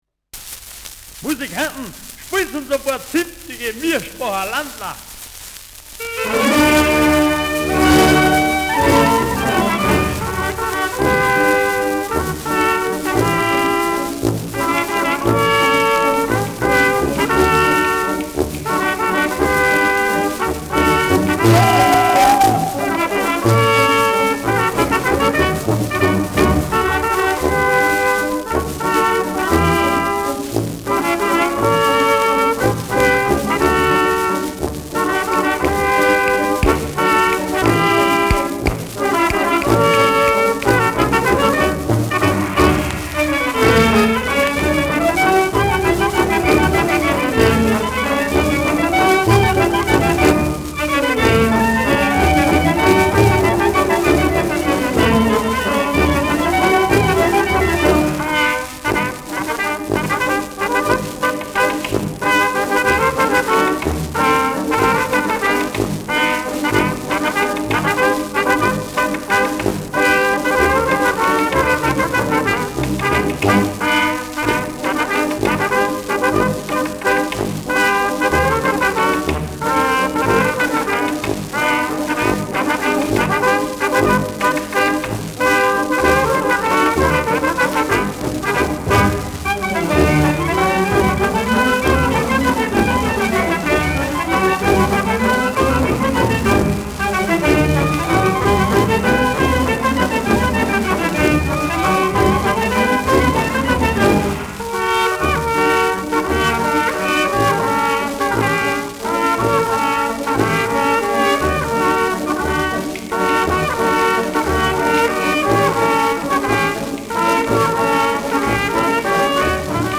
Schellackplatte
präsentes Knistern : leichtes Rauschen
Kapelle Moar (Interpretation)
Mit Juchzern, Klatschgeräuschen und Ausruf am Ende „Hau zua!“
[Berlin] (Aufnahmeort)